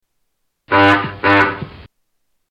A double-whammy from Harpo's horn